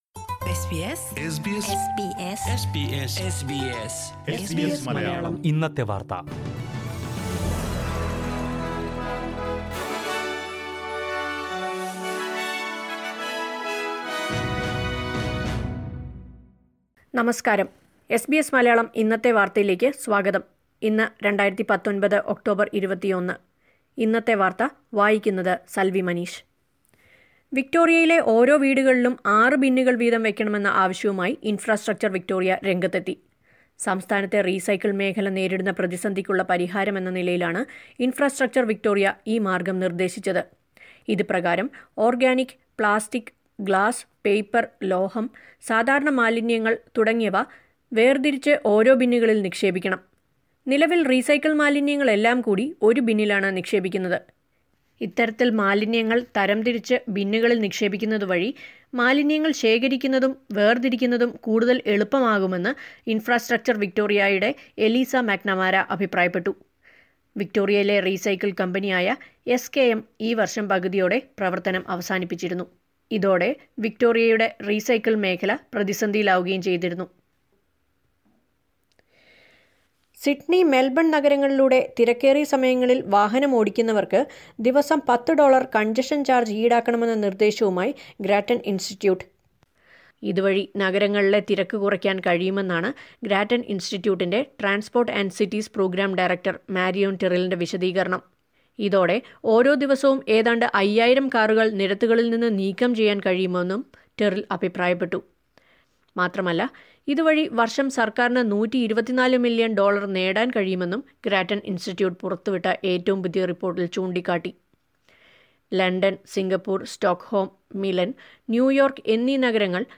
SBS Malayalam Today's News: October 21, 2019